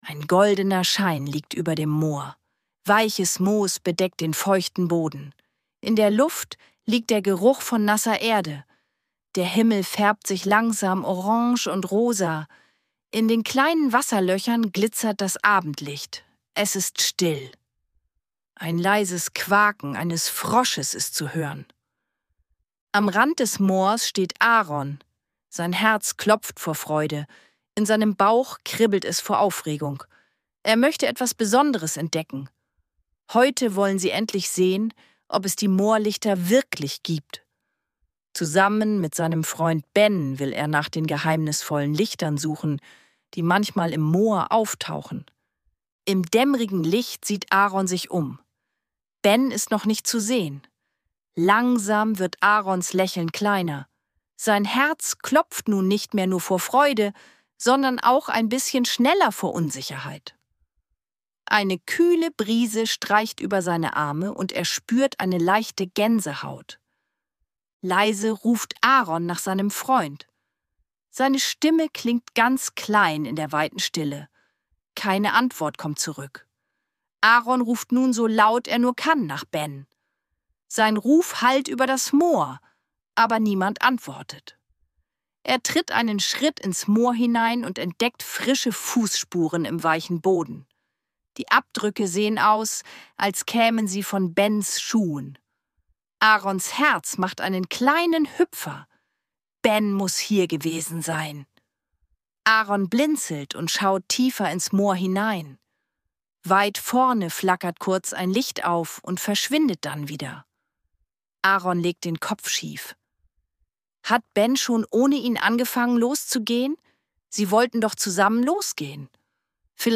Ruhige Kindergeschichten zum Anhören